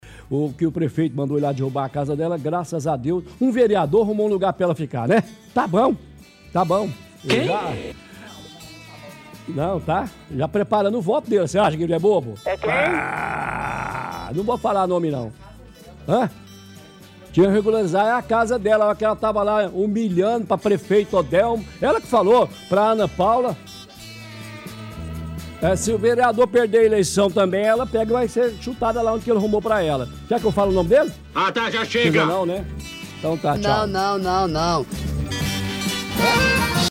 – Transmissão do áudio da matéria passada hoje no chumbo grosso “Sem Casa”.